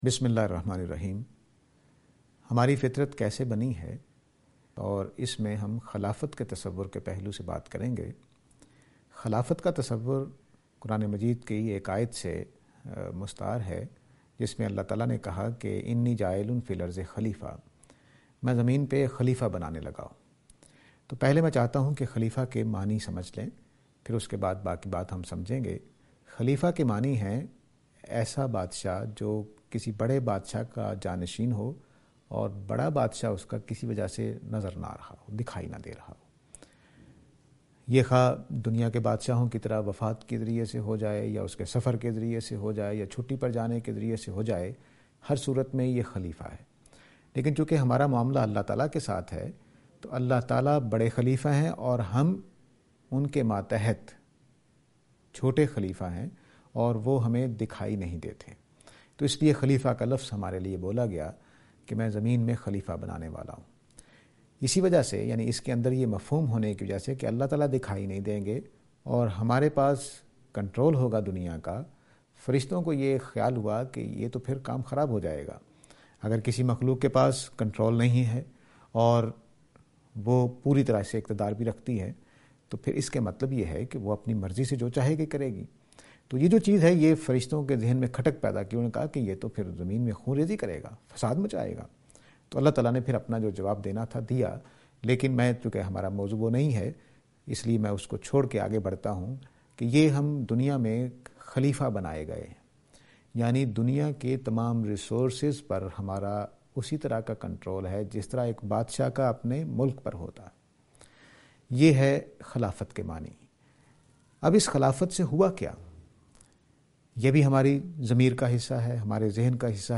This lecture is and attempt to answer the question "Construction of Nature (Khilafah)".